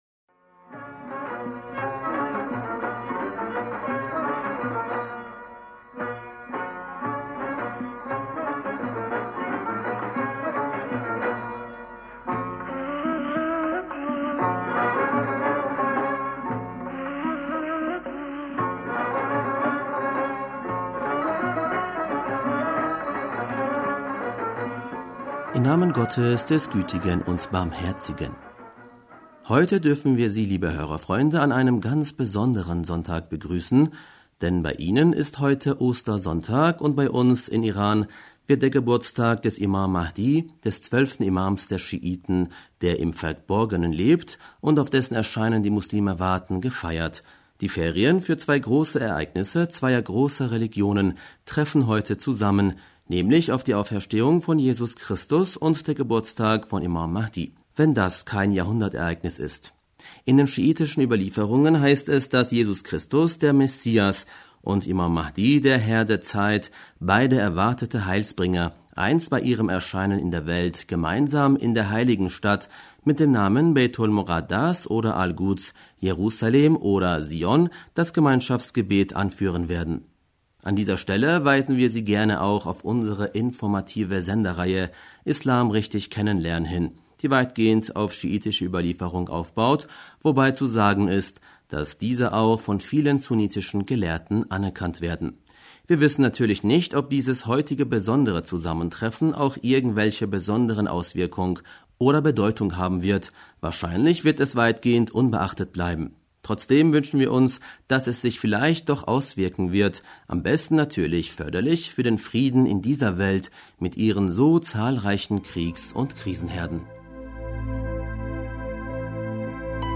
Hörerpostsendung am 21. April 2019
Zum heutigen Osterfest haben wir auch ein wenig Hörerpost bekommen, die wir natürlich gerne vorlesen wollen: „Liebe Freunde